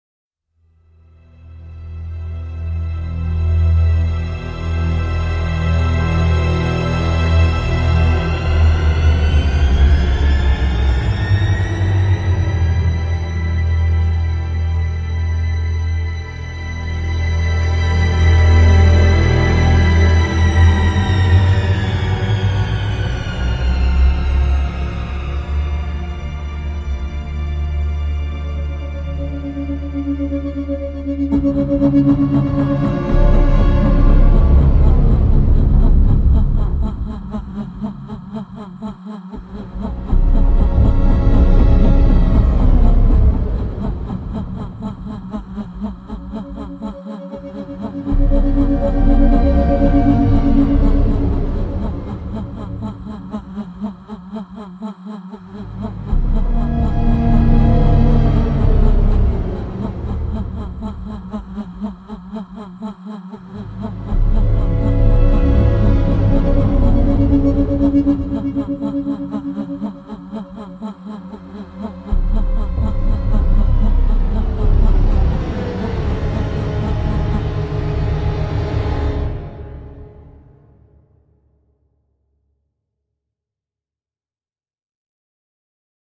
gros travail sur des voix féminines distordues
cordes proches de l’adagio, brusques poussées orchestrales.